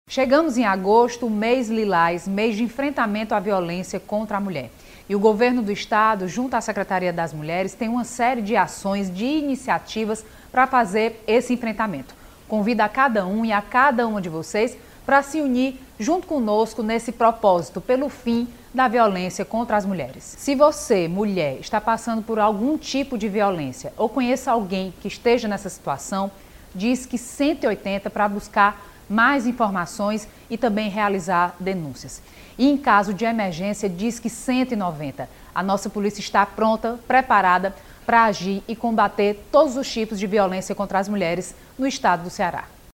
Sobre a importância das ações de enfrentamento durante este mês, fala a vice-governadora e Secretária das Mulheres, Jade Romero.